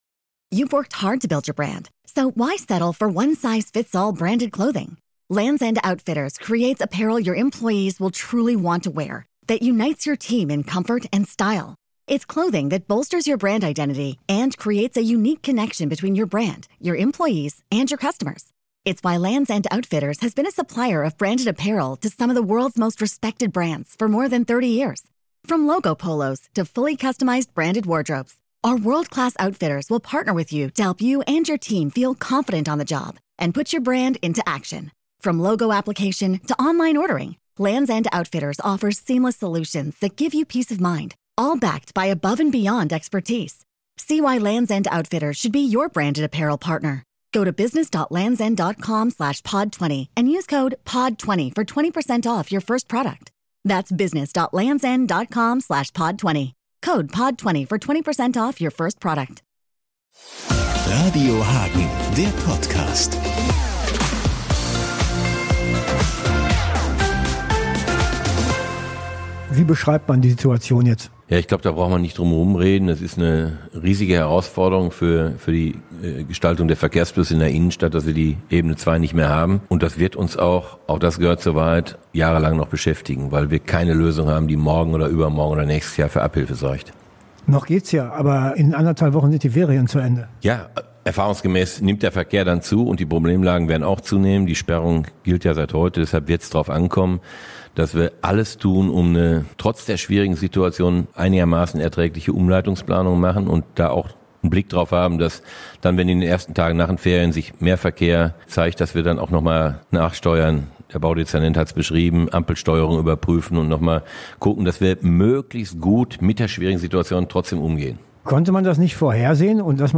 Der Oberbürgermeister im Gespräch